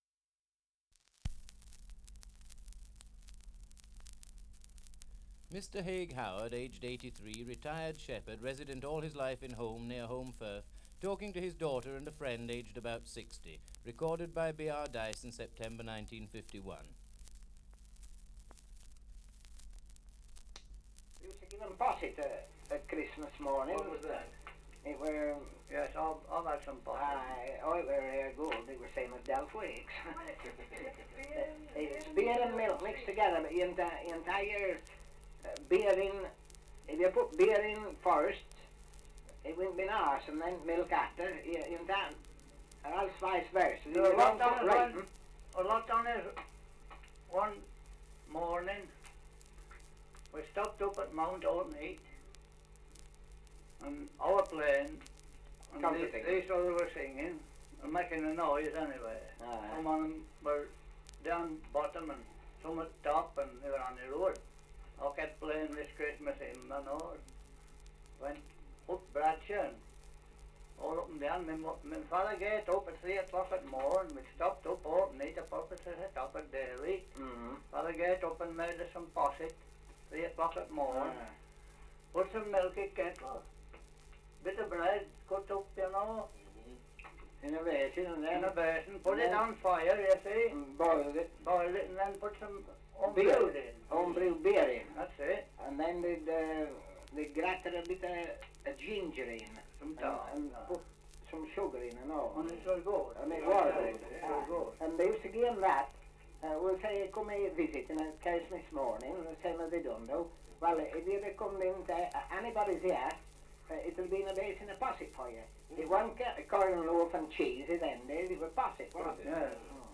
Survey of English Dialects recording in Holmbridge, Yorkshire. Dialect recording in Holmfirth, Yorkshire
78 r.p.m., cellulose nitrate on aluminium